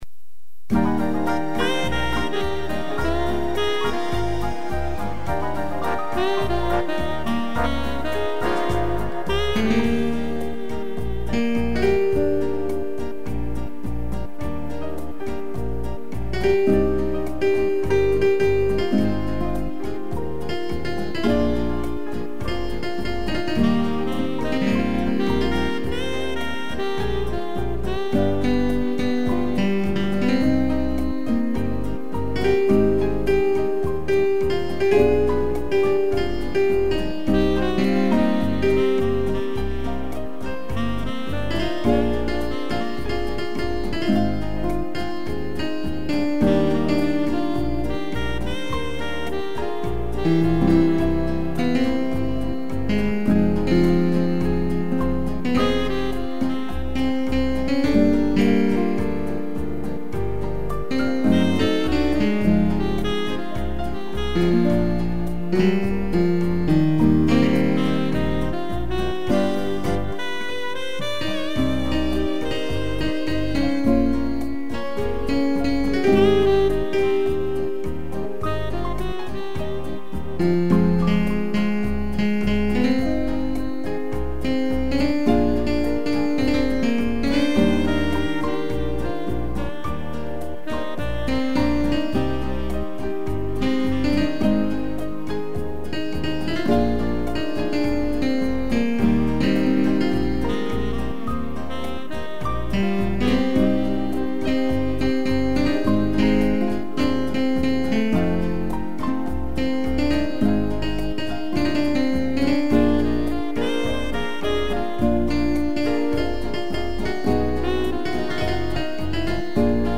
piano e sax
instrumental